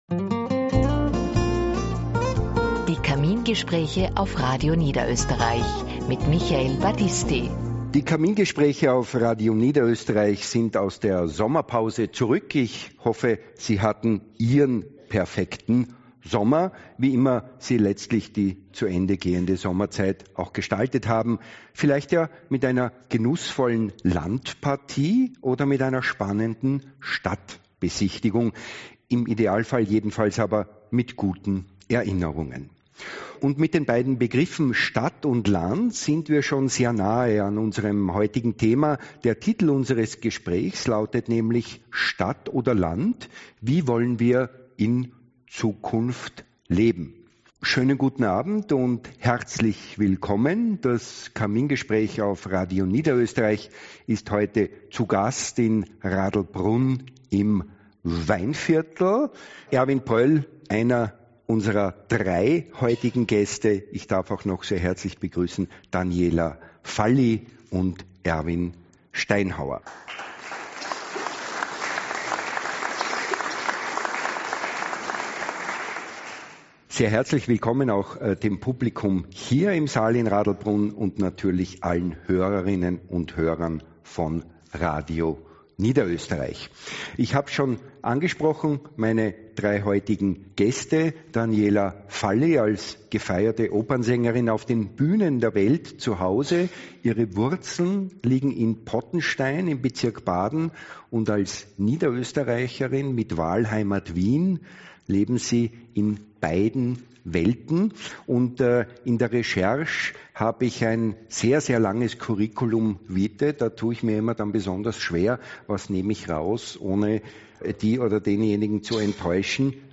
Kamingespraech_Stadt_Land.mp3